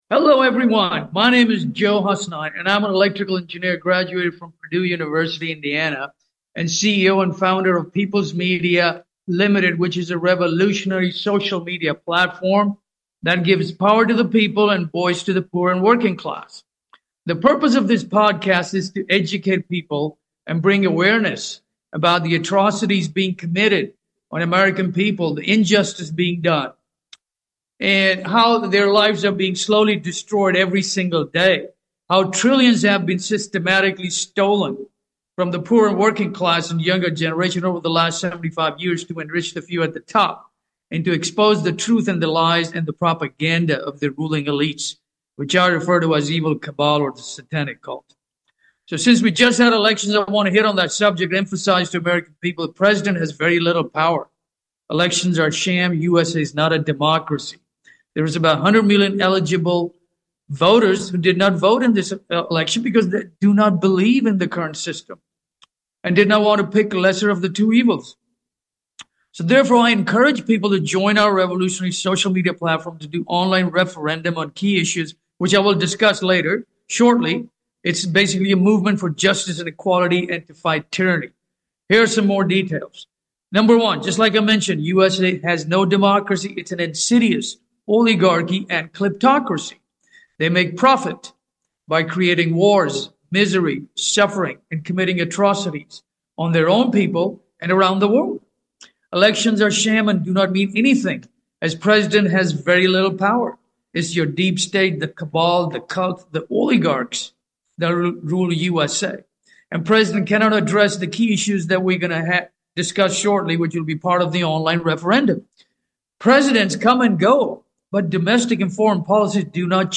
PeoplesMedia Movement Talk Show